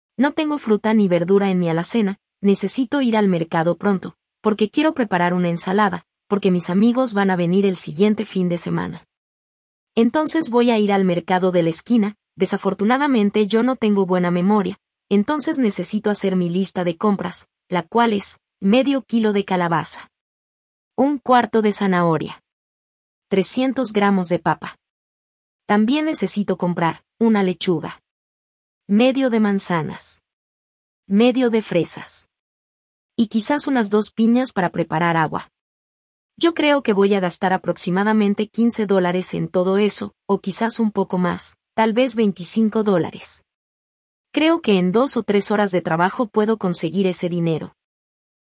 Difficulty: Beginner (A2)
Version A2 – B1 Español Neutro